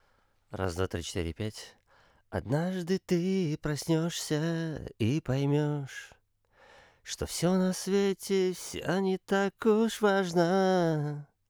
Avalon 737 (железный) - сильно красит звук микрофона
А именно: очень сильно смягчает верхние частоты, прям заметно смягчает, низкие частоты наоборот становятся более пузатыми богатыми.